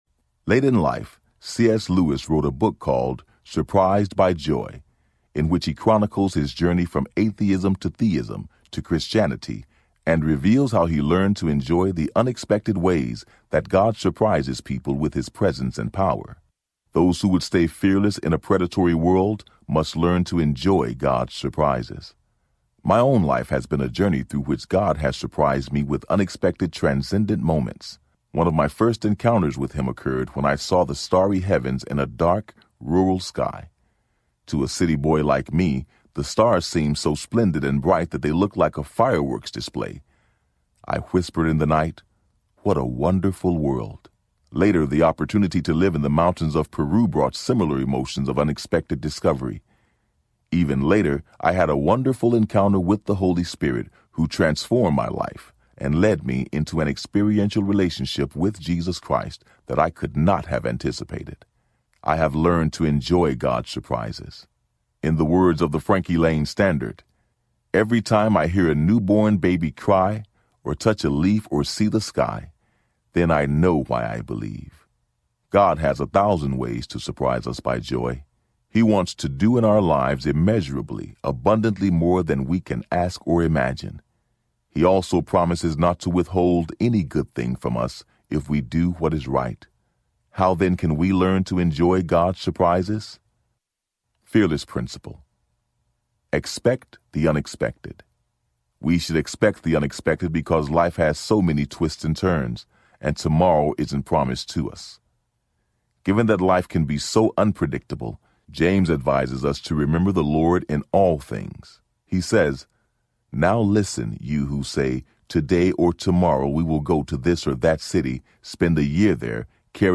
Nothing to Fear Audiobook
Narrator